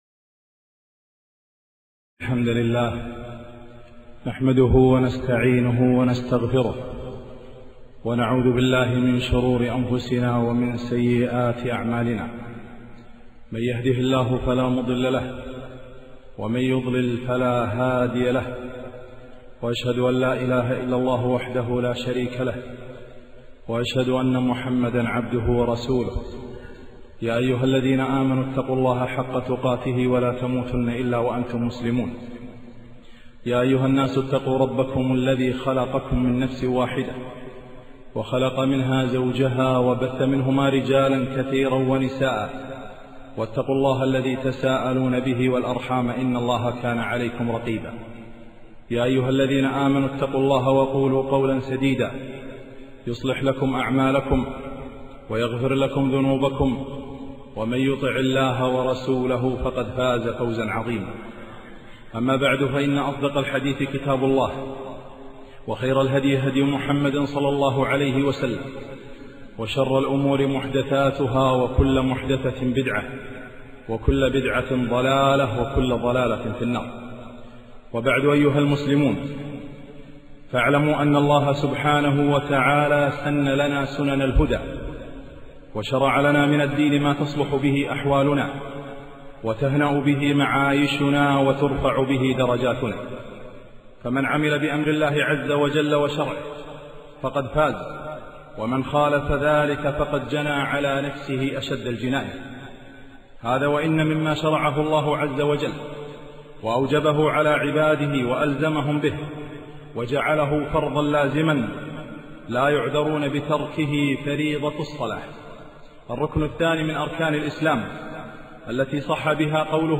خطبة - حي على الصلاة